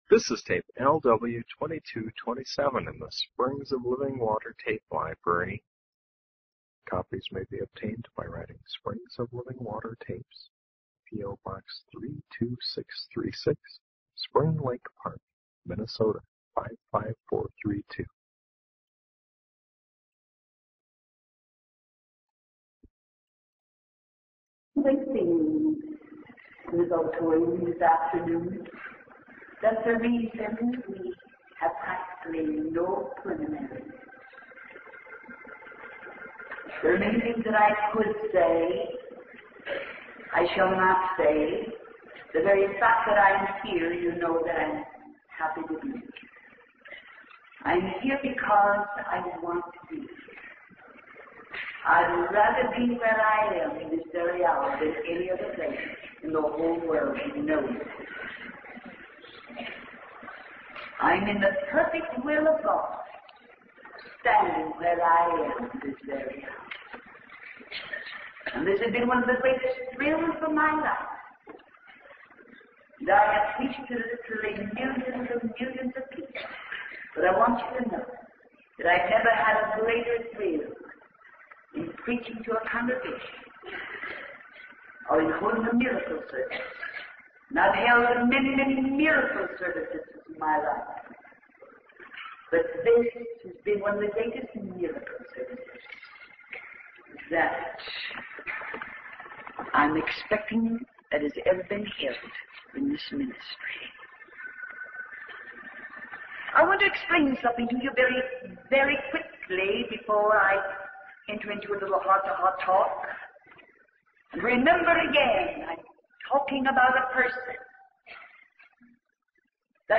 In this sermon, the preacher emphasizes the weight of responsibility that comes with preaching the word of God. He acknowledges the sacrifices made by the audience to be present and expresses gratitude for their dedication.